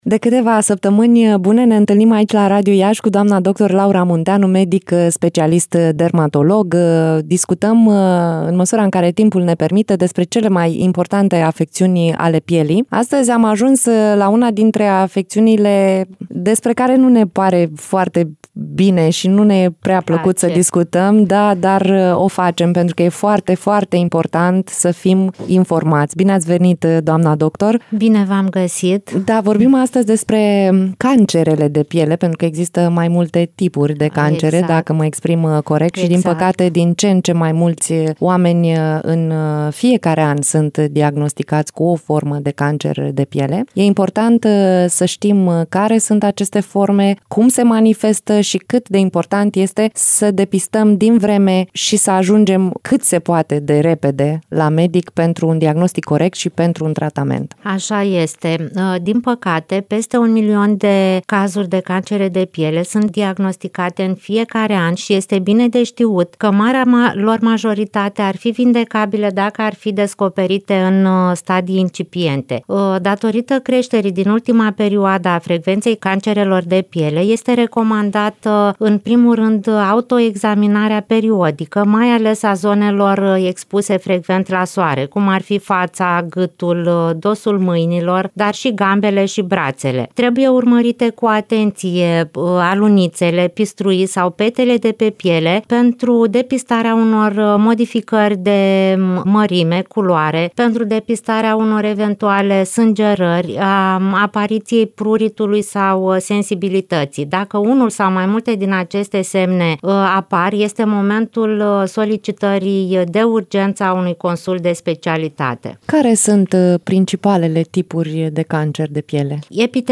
în direct la “Bună Dimineața”